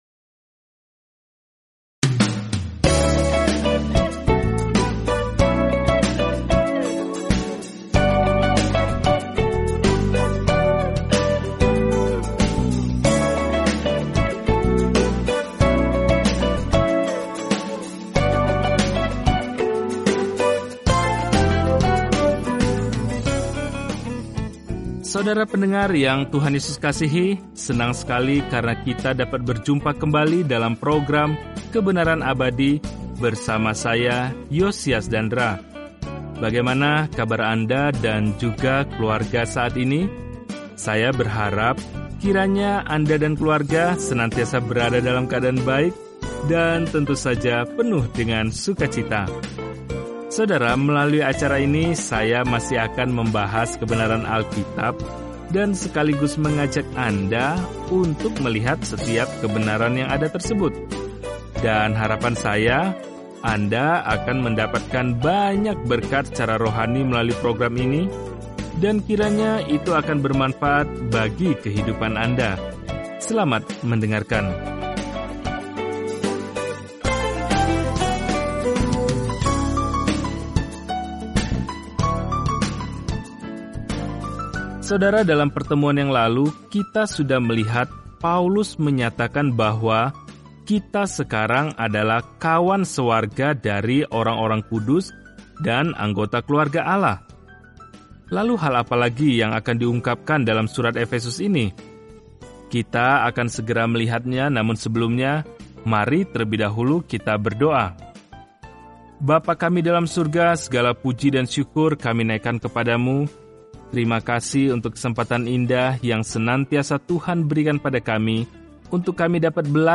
Firman Tuhan, Alkitab Efesus 3:1-13 Hari 13 Mulai Rencana ini Hari 15 Tentang Rencana ini Dari keindahan yang dikehendaki Allah bagi anak-anak-Nya, surat kepada jemaat Efesus menjelaskan bagaimana berjalan dalam kasih karunia, damai sejahtera, dan kasih Allah. Jelajahi Efesus setiap hari sambil mendengarkan pelajaran audio dan membaca ayat-ayat tertentu dari firman Tuhan.